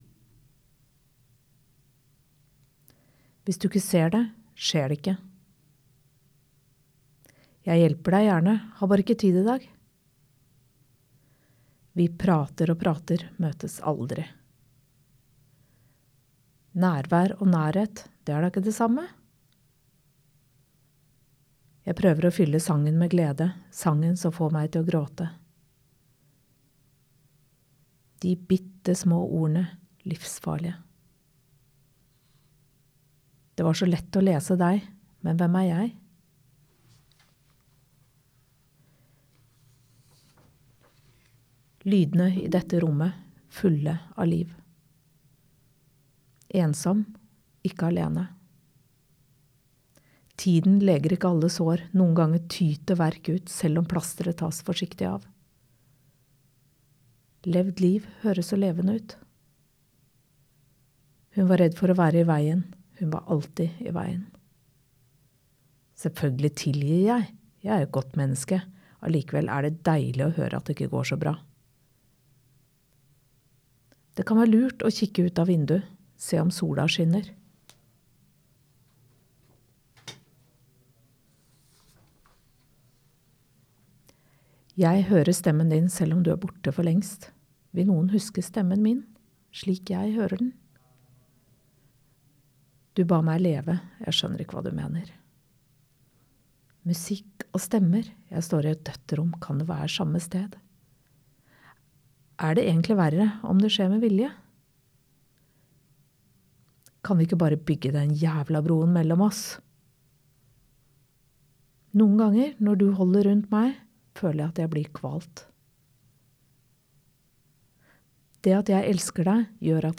Her kan du høre alle tekstene i boka, opplest av meg: